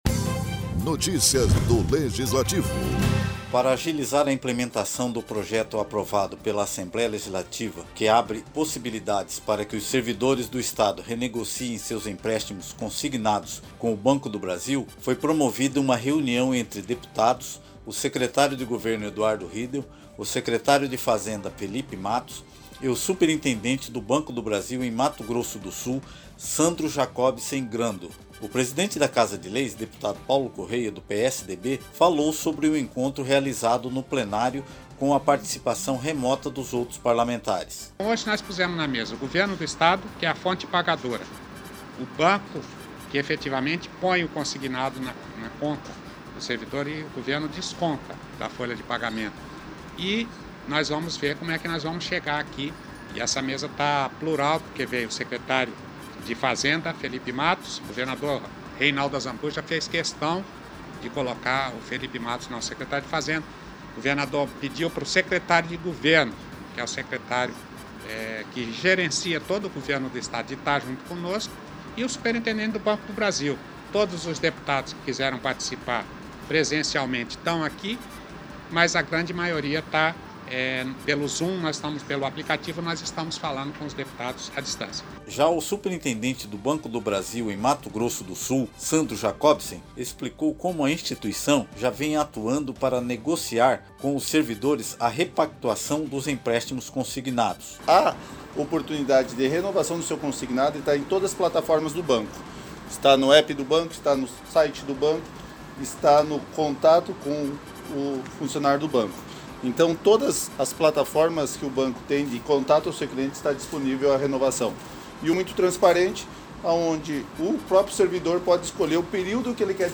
O deputado Paulo Correa falou sobre o encontro realizado no plenário  com a participação remota de  outros parlamentares.
Locução e Produção: